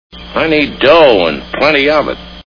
The Treasure of Sierra Madre Movie Sound Bites